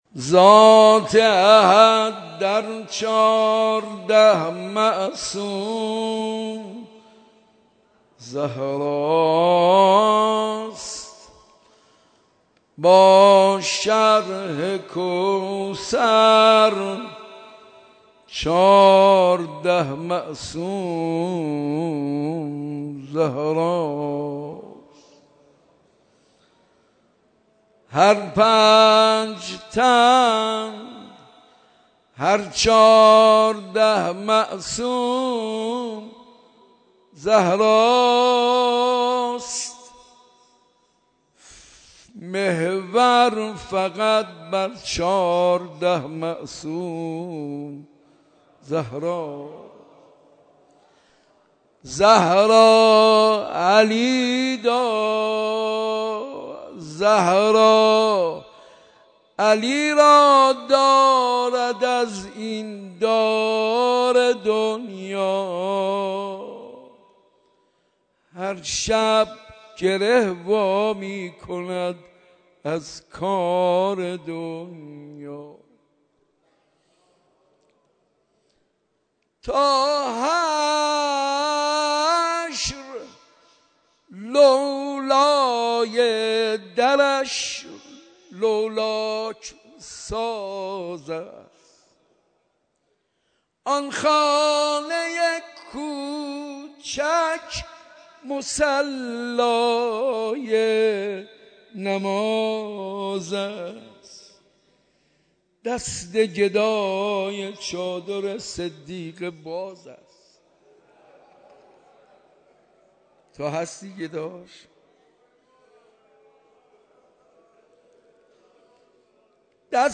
اولین شب مراسم عزاداری حضرت فاطمه‌ی زهرا (سلام‌الله‌علیها)، با حضور حضرت آیت‌الله خامنه‌ای رهبر انقلاب اسلامی، مردم دلداده‌ی اهل بیت عصمت و طهارت (علیهم‌السلام)با سخنرانی حجت الاسلام صدیقی و مداحی حاج منصور ارضی در حسینیه‌ی امام خمینی (رحمه‌الله) برگزار شد.
مداحی